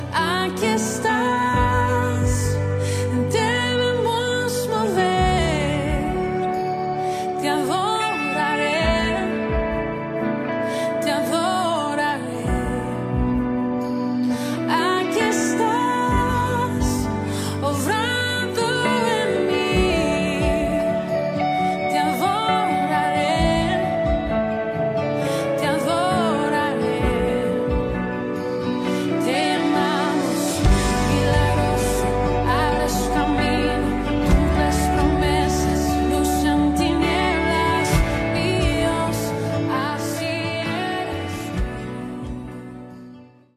Cançó religiosa